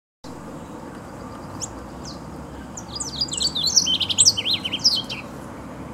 Pia-cobra (Geothlypis velata)
Nome em Inglês: Southern Yellowthroat
Fase da vida: Adulto
Localidade ou área protegida: Reserva Ecológica Costanera Sur (RECS)
Condição: Selvagem
Certeza: Fotografado, Gravado Vocal